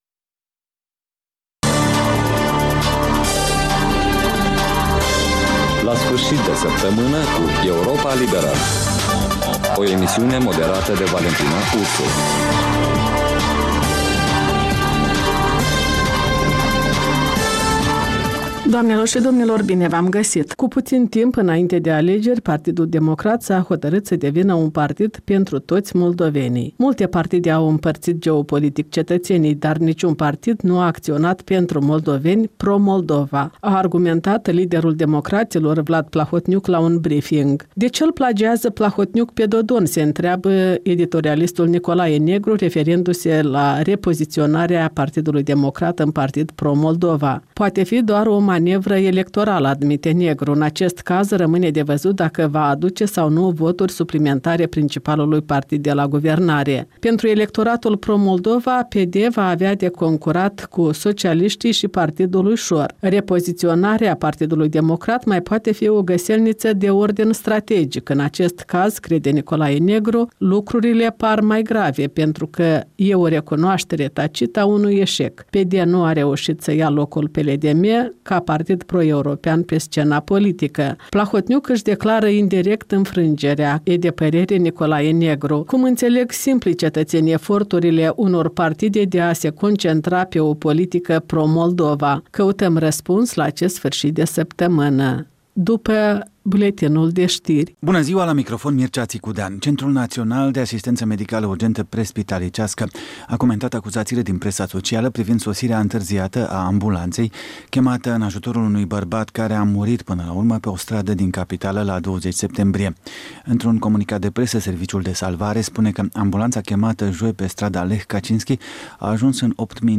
In fiecare sîmbătă, un invitat al Europei Libere semneaza „Jurnalul săptămînal”.